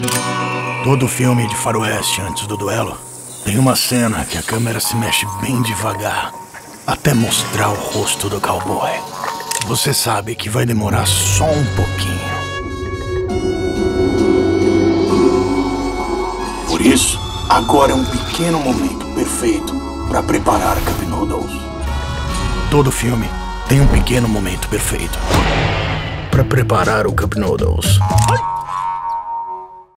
Demonstração Comercial
Animação
Tenho um home studio de nível profissional.
Jovem adulto